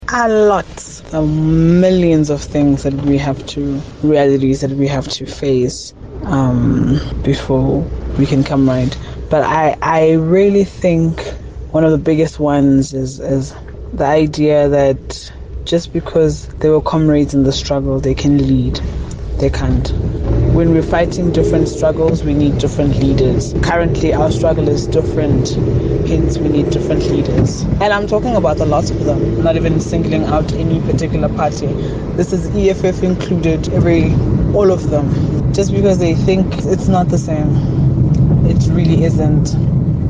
However, as was evident by listener’s views on Kaya Drive, not many share the President’s sentiments.